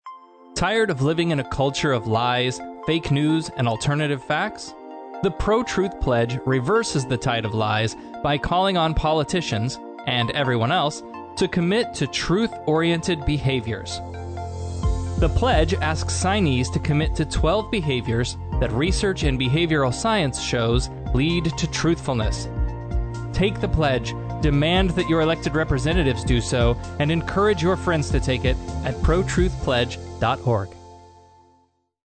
PSAs for Podcasts and Radio Shows